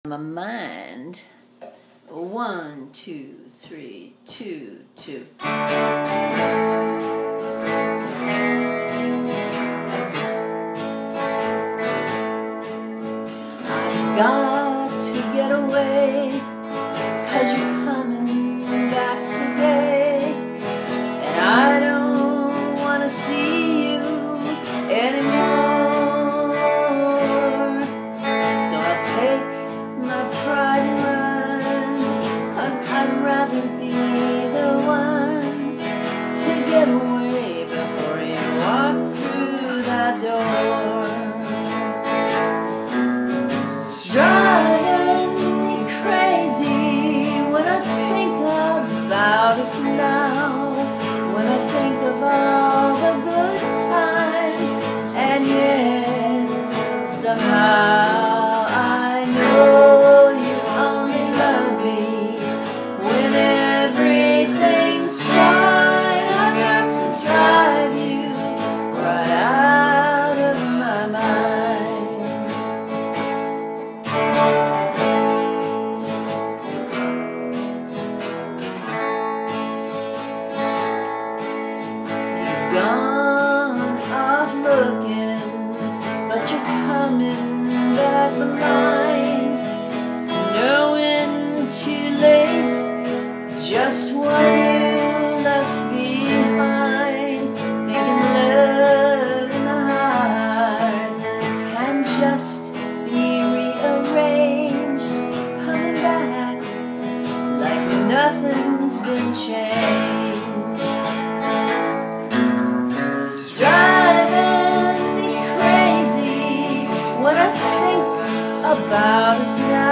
AUDIO »»» w/ harmony & lead